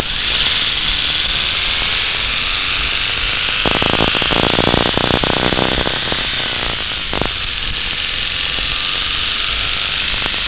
Knock detection
You can hear the racket made by the engine, but the knock is the harsh rasp that can be heard at the end of the recording.
knock.wav